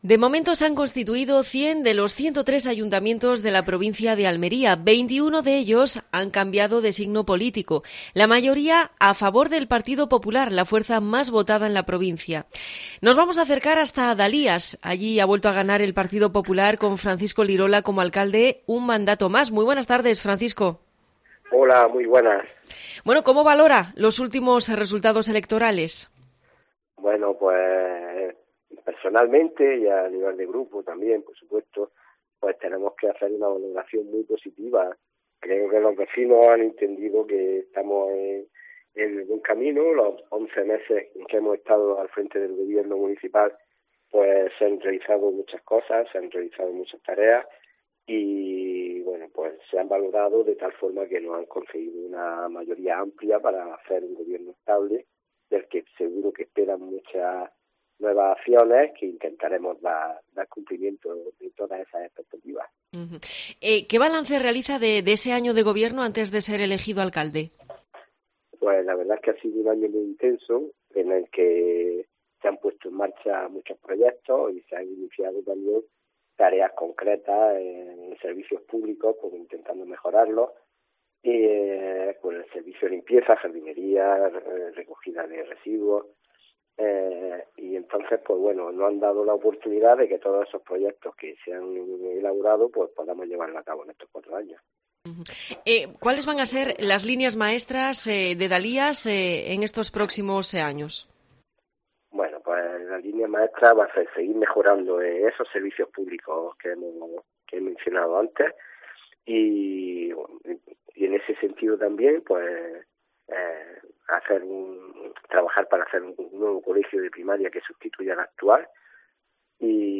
Entrevista al alcalde de Dalías, Francisco Lirola